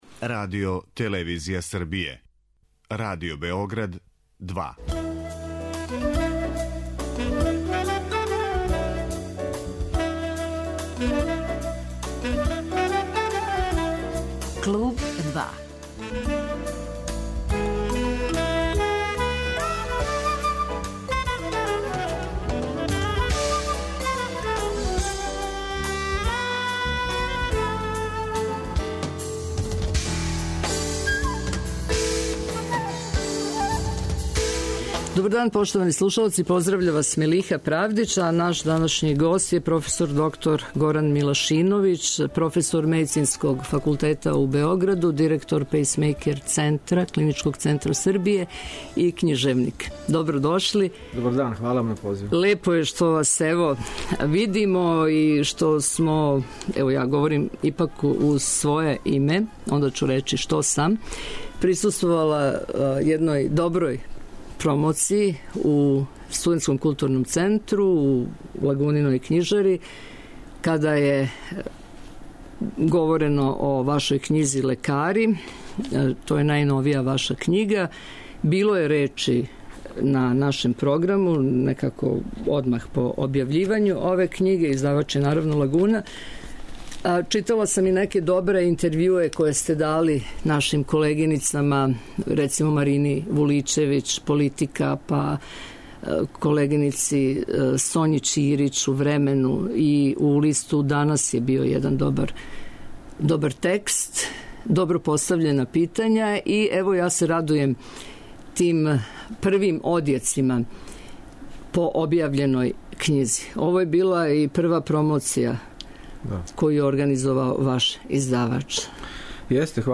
Како исцртава своје ликове, с колико вештине улази у суштину њихових, иначе замршених односа како у послу, тако и у приватном животу, како описује њихове емоције руковођене често жељом за успехом - све су то теме за данашњи разговор.